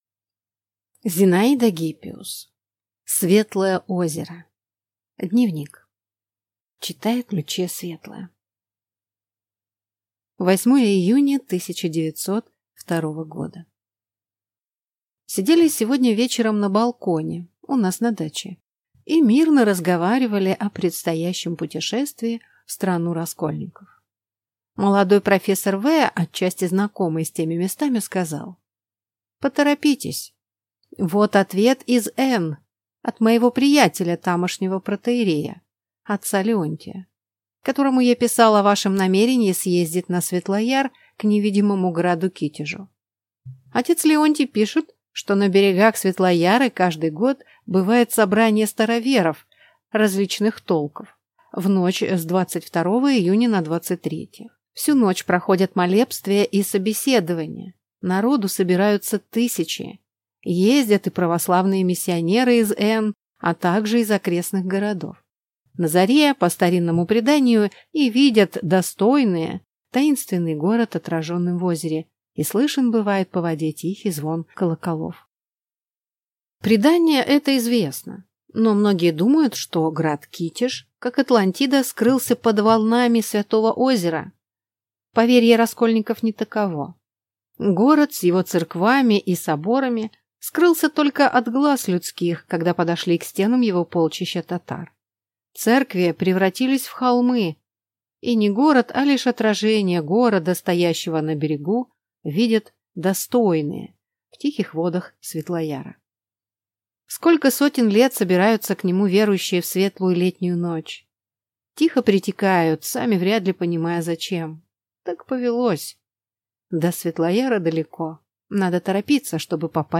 Аудиокнига Светлое озеро | Библиотека аудиокниг
Прослушать и бесплатно скачать фрагмент аудиокниги